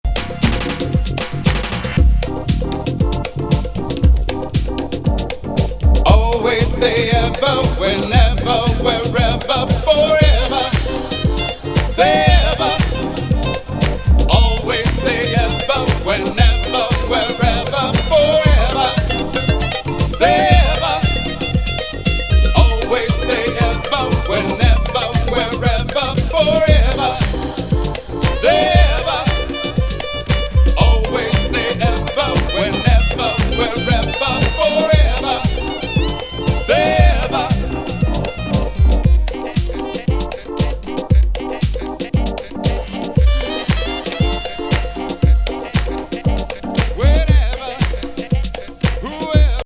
アンビエント、ビート・ダウン、ジャズ&エレクトリックな、ありそうでない絶妙な
グルーヴの好ミッド・テンポ・チューン!ディープでレイドバッキンな浸り系の
トラック。程よくエレクトリックなSEもナイス!